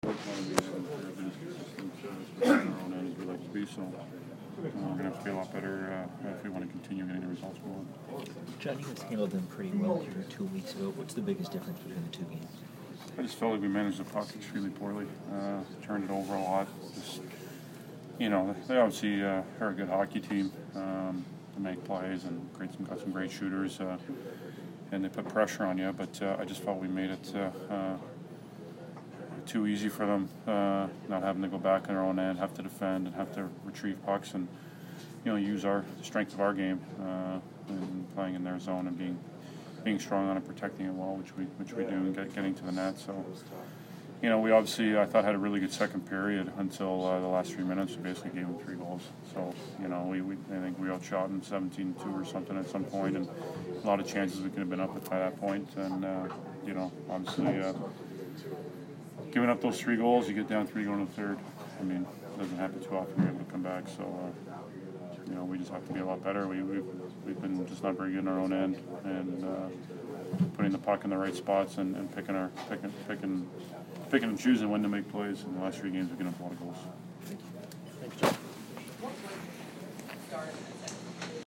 John Tavares Post-Game 12/5